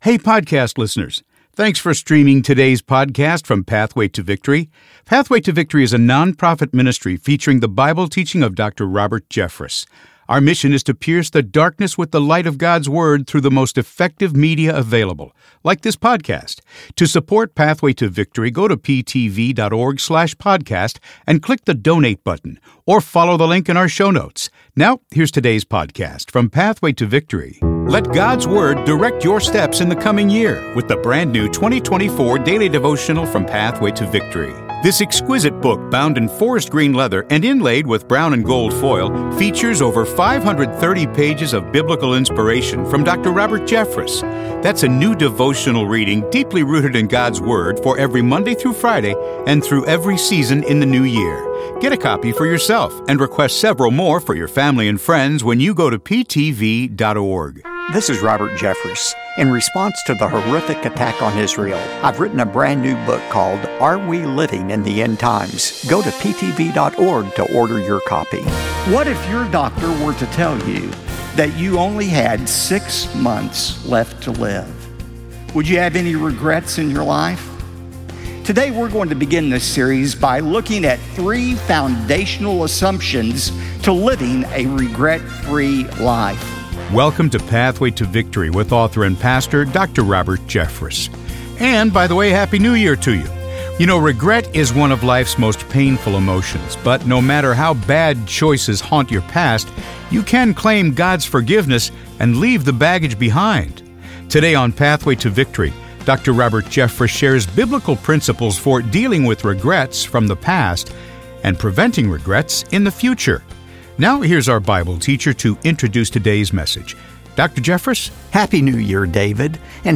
The Truth Network Radio
Dr. Robert Jeffress discusses the importance of living a regret-free life, sharing biblical principles for dealing with past regrets and preventing future ones. He explores the idea that a fresh start and clean slate can help individuals overcome disappointment and say goodbye to regret.